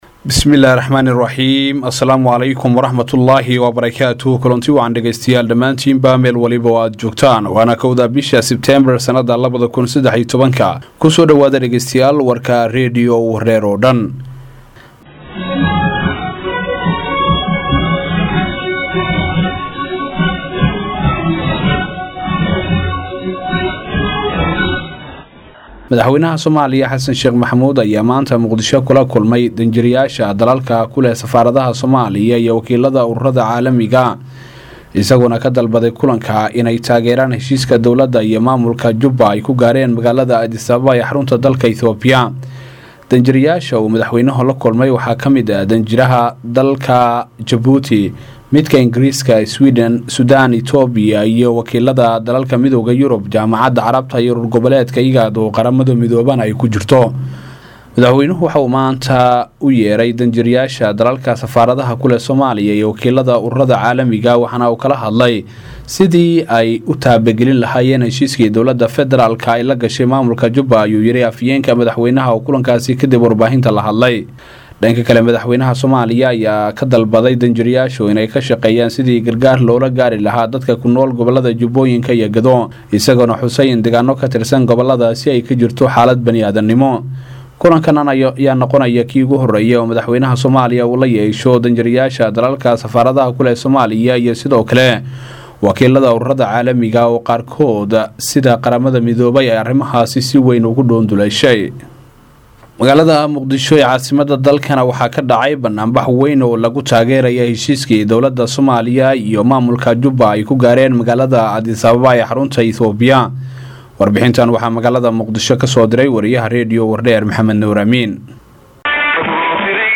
• Audio Daily News